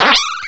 sovereignx/sound/direct_sound_samples/cries/sandile.aif at master